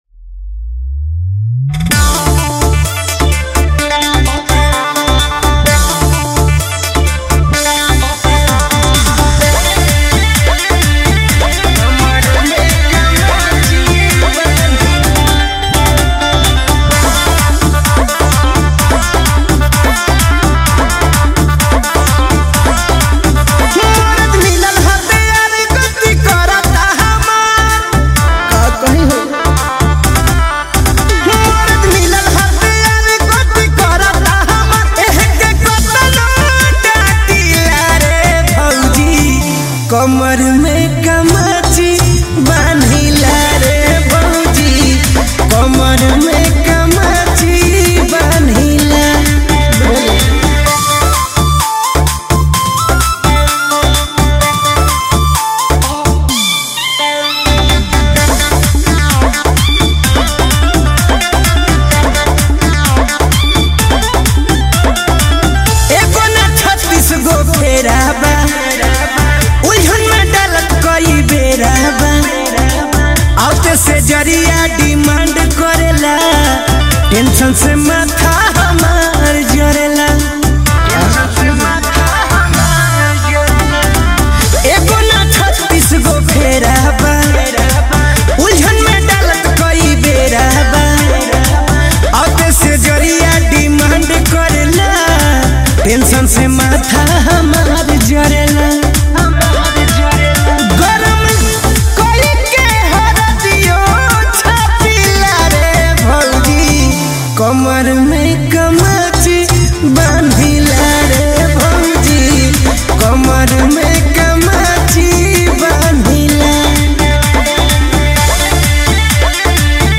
Bhojpuri